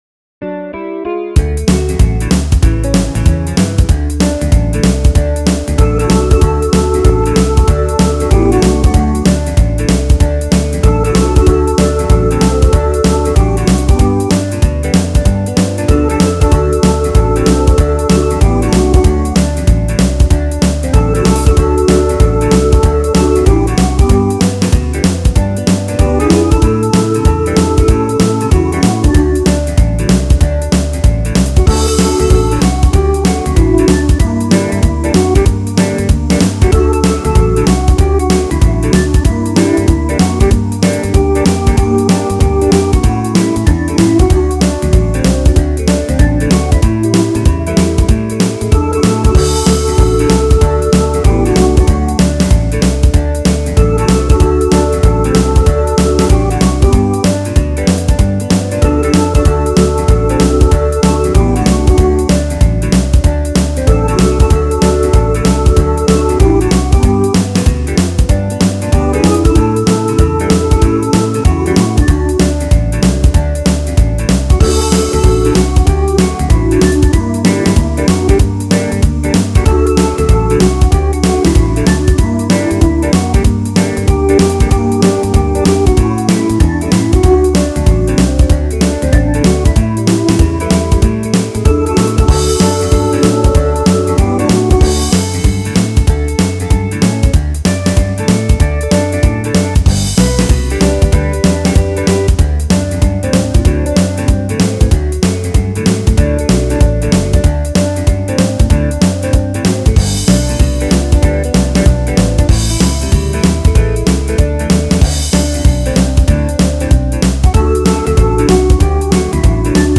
Try it at your next gig (it’s on the Country list).